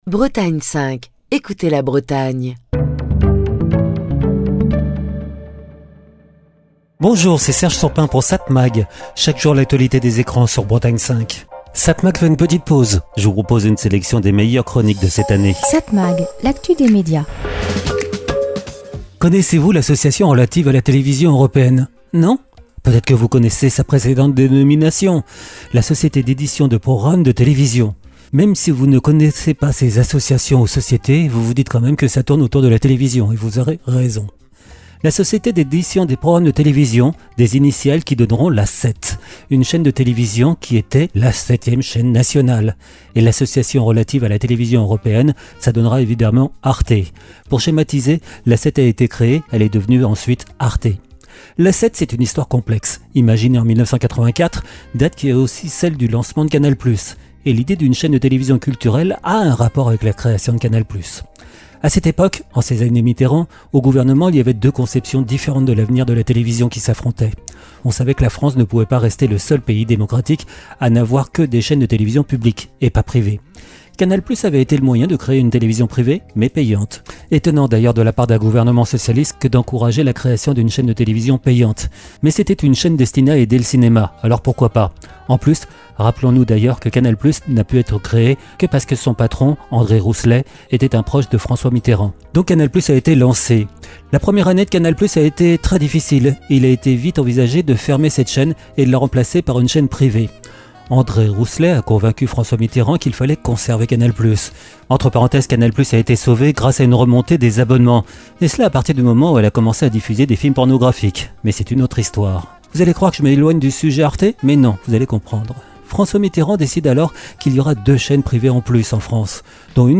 Chronique du 14 juillet 2025.